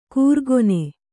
♪ kūrgone